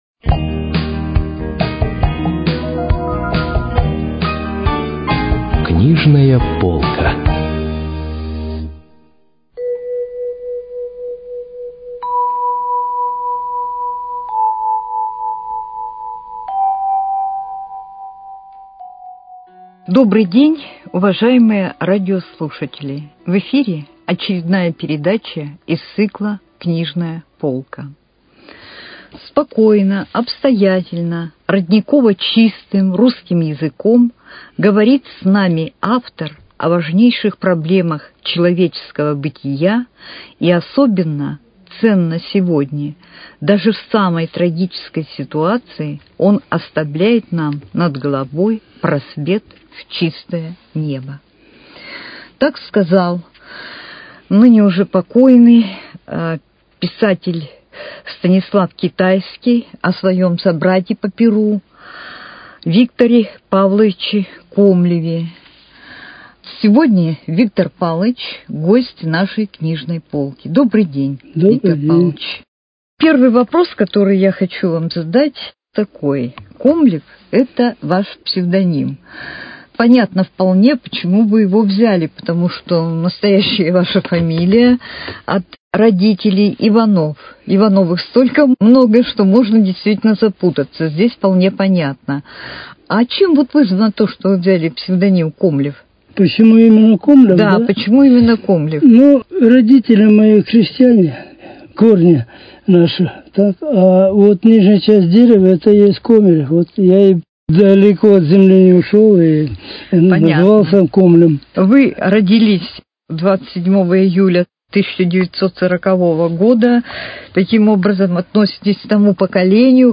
Книжная полка: Беседа